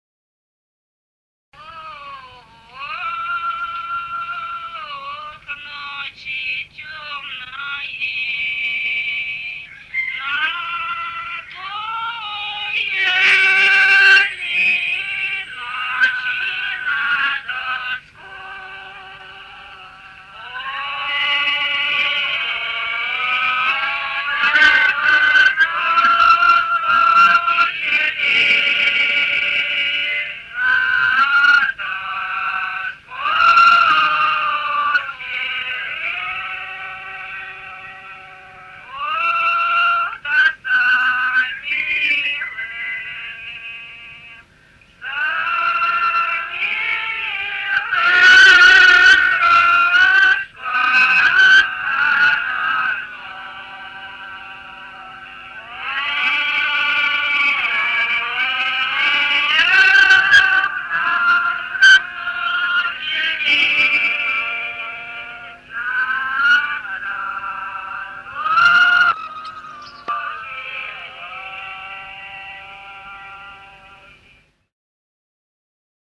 Русские народные песни Владимирской области 3. Ох, ночи тёмные (лирическая протяжная) с. Пополутово Муромского района Владимирской области.